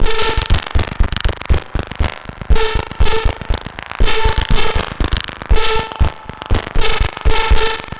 Abstract Rhythm 26.wav